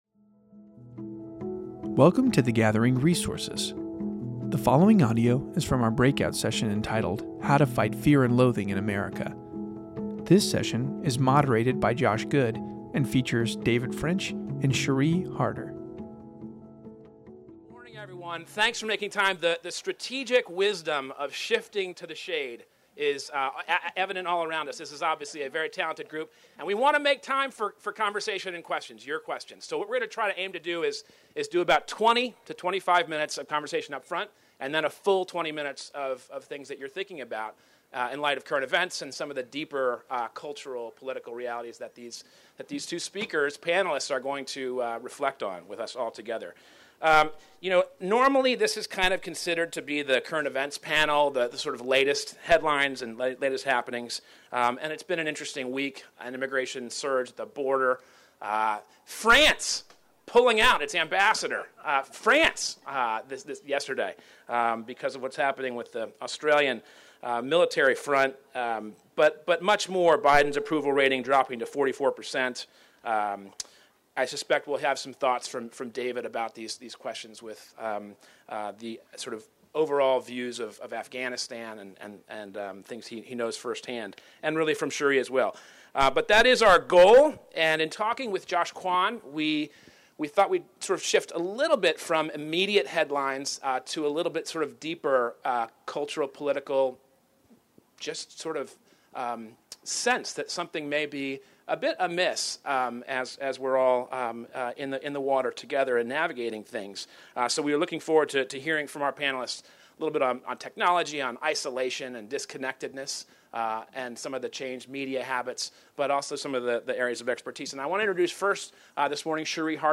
The following audio is from our 2021 conference breakout session entitled, How to fight fear and loathing in America?